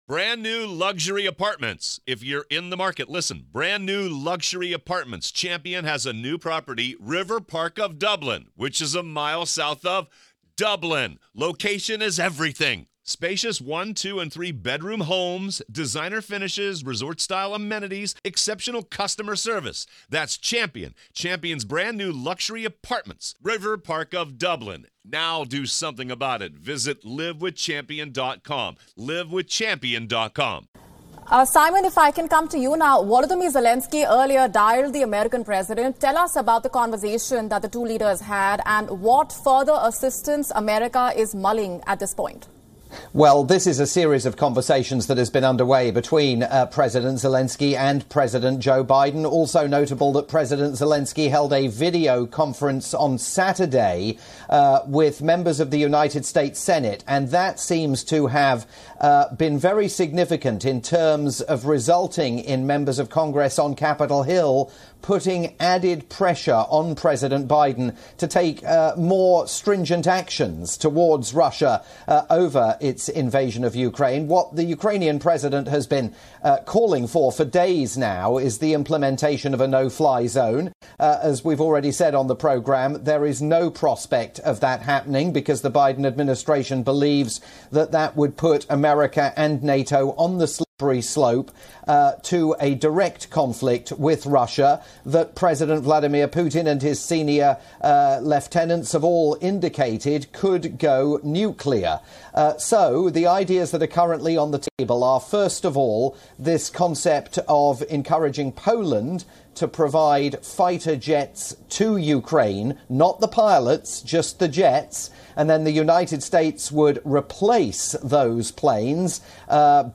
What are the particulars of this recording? live update for India's all-news WION